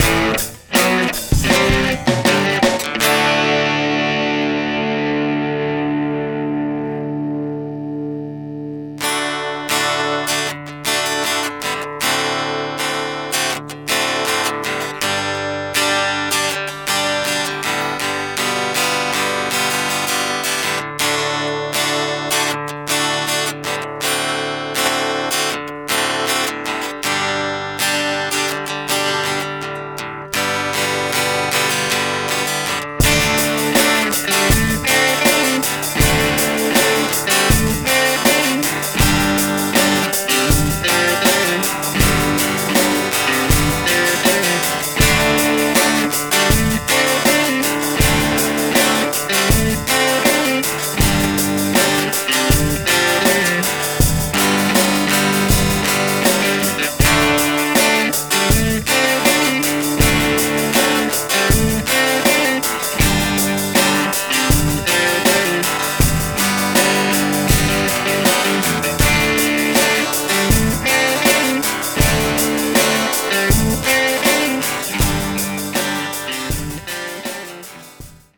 We both decided to kick out the pick guitar for now because it muddles up the track and detracts from the simple formula of the song. The bass stays out (this was the bass from the Ragtime version of old and didn’t go) and just focus on drums, acoustic and main lead guitar for now.